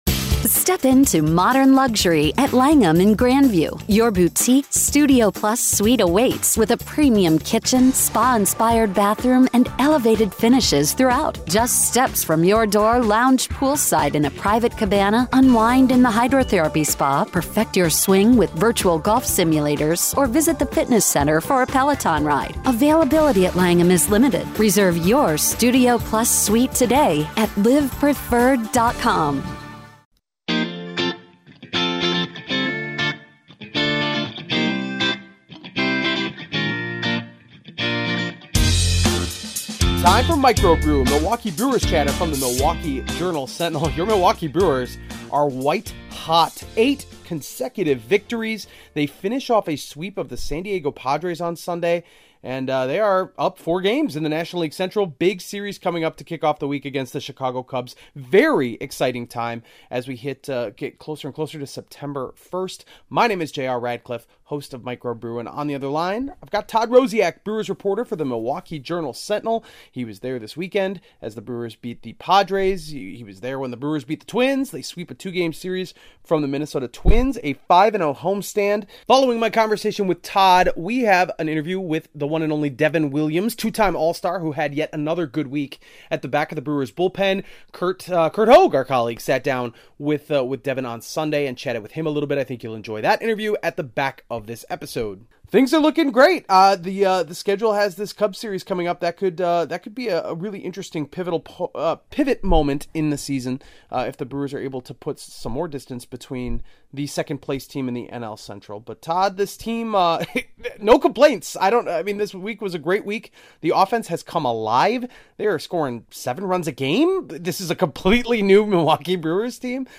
A conversation with Devin Williams and breaking down the white-hot Brewers with the Cubs up next (08.28.2023)